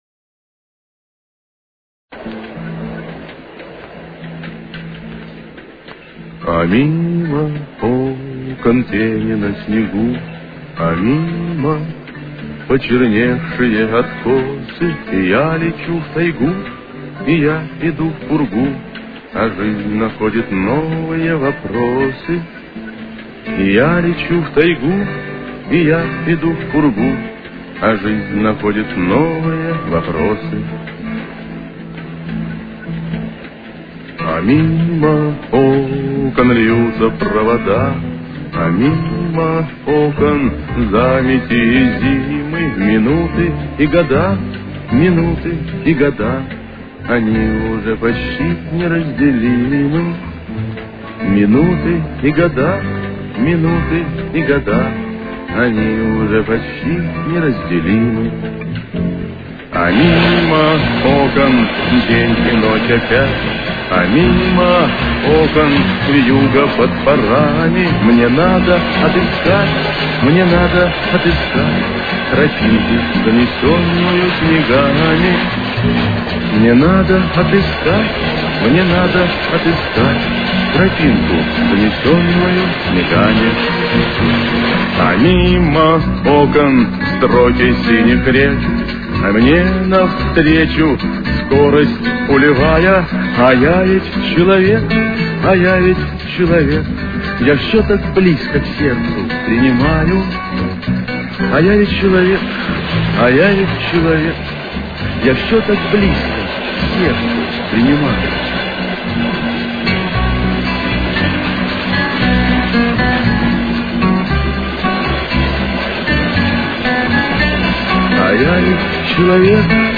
с очень низким качеством (16 – 32 кБит/с).
Темп: 117.